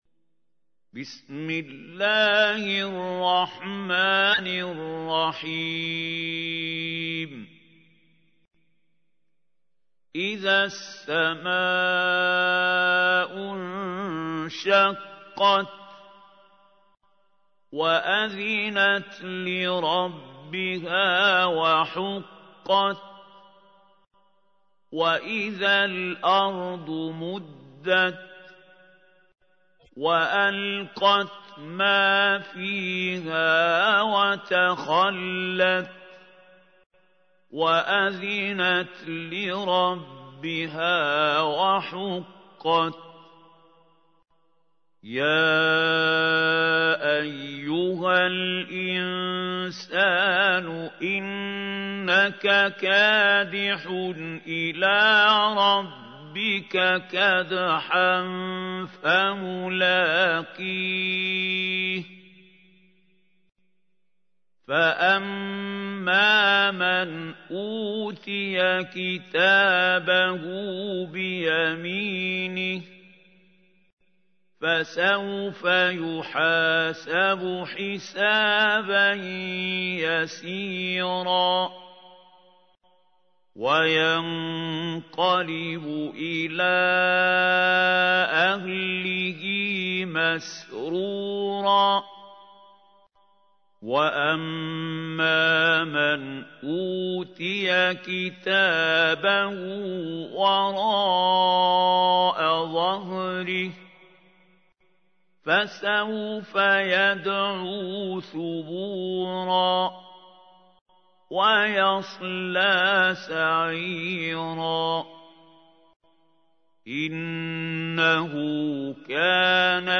تحميل : 84. سورة الانشقاق / القارئ محمود خليل الحصري / القرآن الكريم / موقع يا حسين